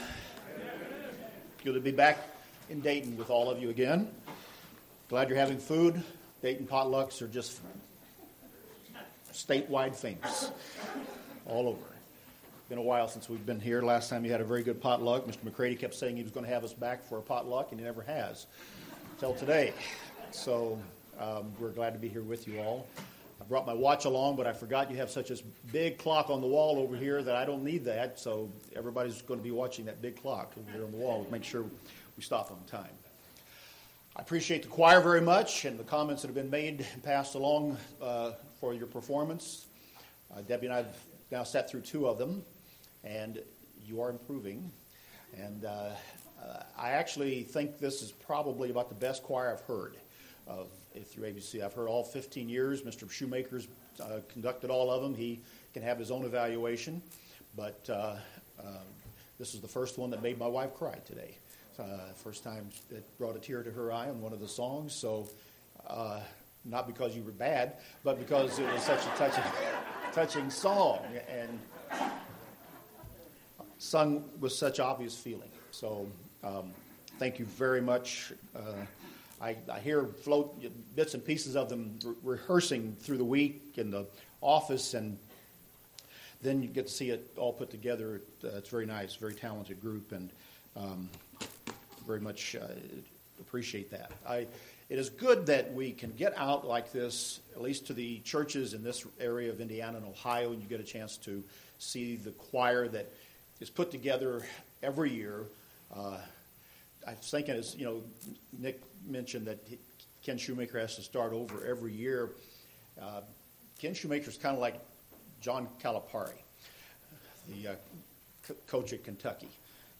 Look into the Faith of Noah in relation to the Passover season UCG Sermon Transcript This transcript was generated by AI and may contain errors.